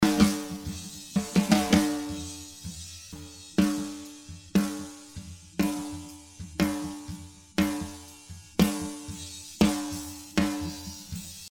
Hier nur ein Noise-IR und, etwas überdeutlich zugemischt, [auf eine shitty Snare-Spur ] das Noise-Signal: Ausgangssignal Anhang anzeigen 139594 NoiseVerb via Mconvolve = free = primitiver free-convolver Anhang anzeigen 139595